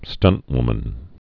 (stŭntwmən)